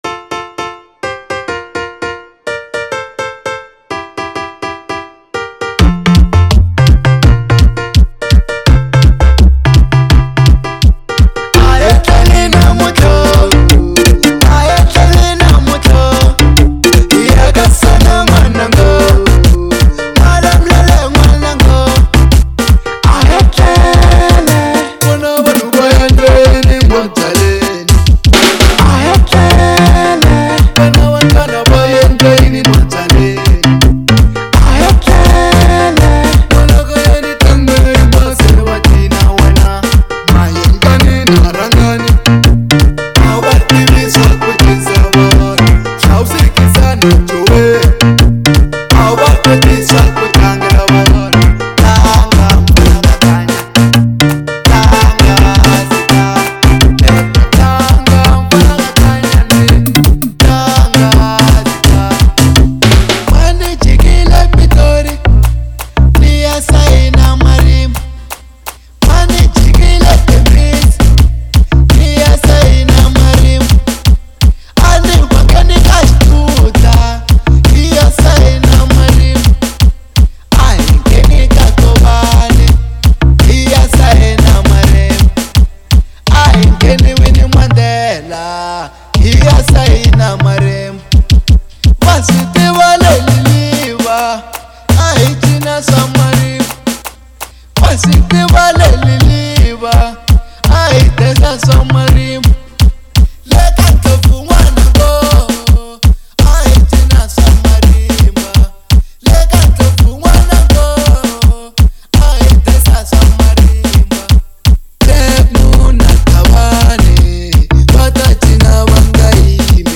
03:16 Genre : Xitsonga Size